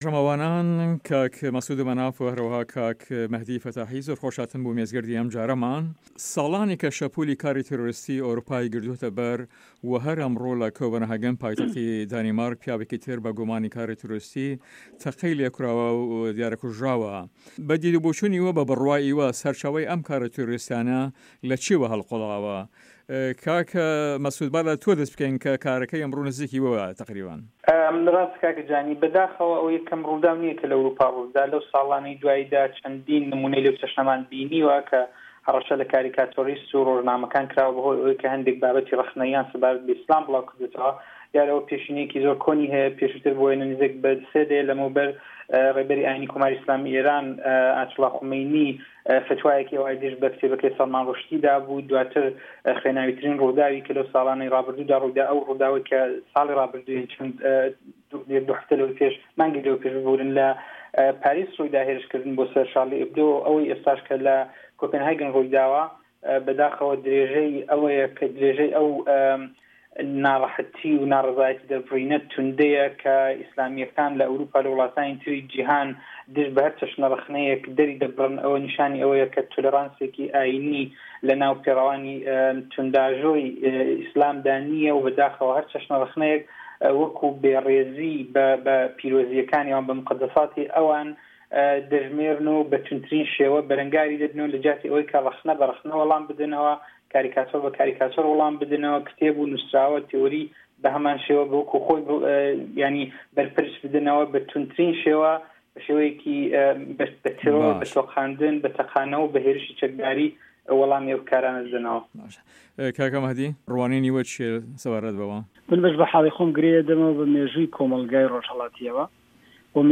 Round Table